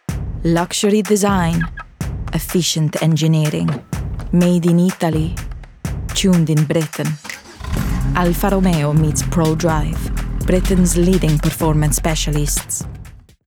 Italian Accent
Conversational, Bright, Upbeat, Natural, Italian